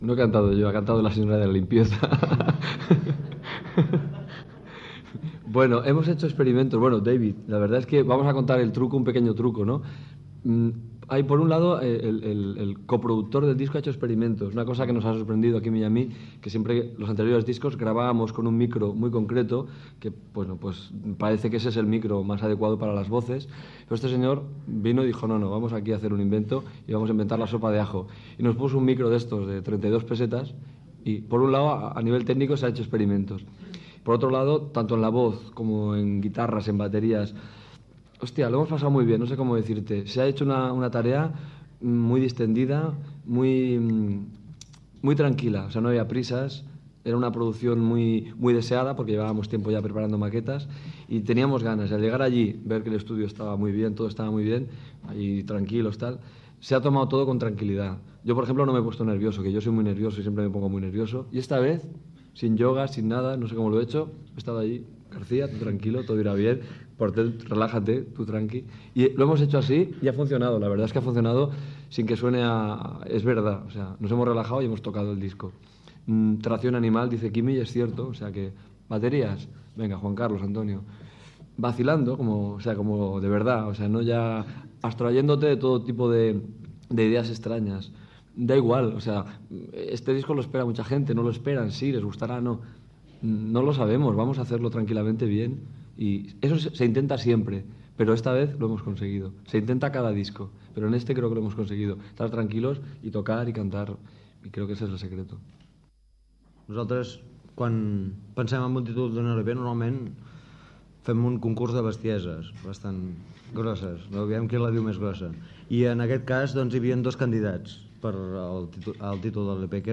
Roda de premsa del grup El último de la Fila amb Manolo García i Quimi Portet, que actuaran a la sala Zeleste el 9 de març de 1993
Fragment extret del programa "Com sonava" emès el 26 de novembre de 2016 per Ràdio 4.